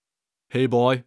dhan_voice_serve.wav